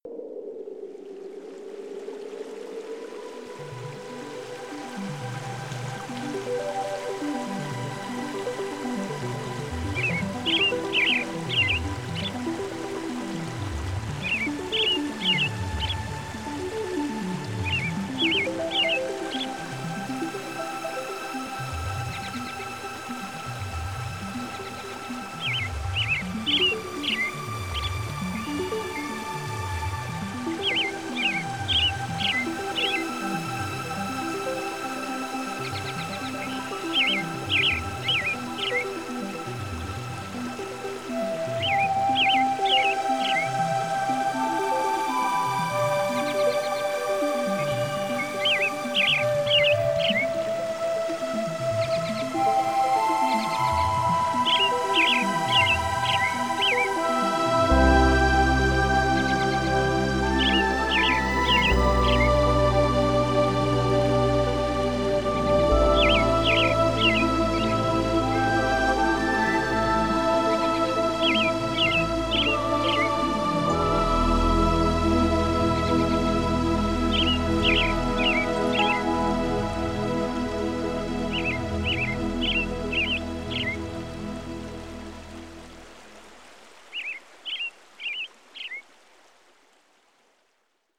他建议将精心制作的乐曲与大自然的天籁结合，相得益彰之下，更能营造一种动人的听觉环境。